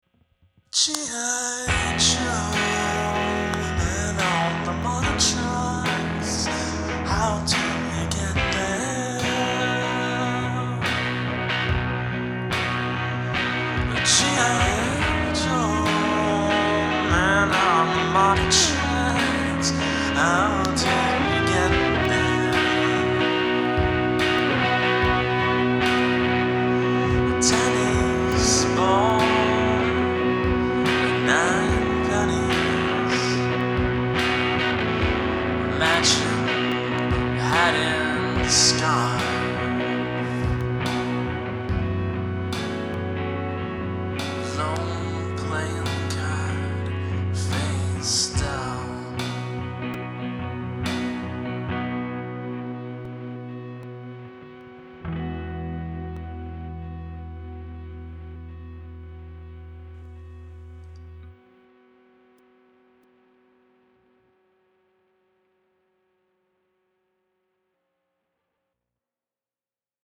I've always loved songs like Sonic Youth's "In The Kingdom 19" Â or the Minutemen's "Take 5, D" - songs with spoken word over them, or songs with found lyrics that have a weird poetry to them.